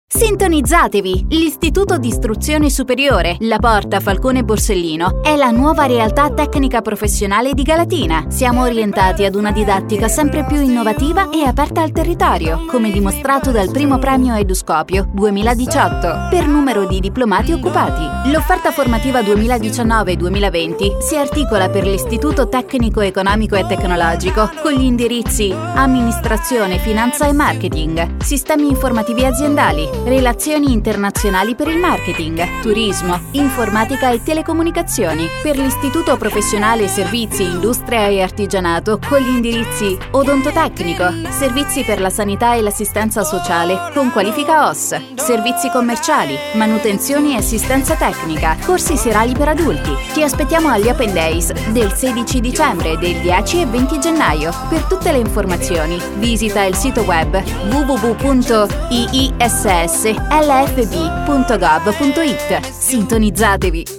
Spot radiofonico orientamento A.S. 2019-2020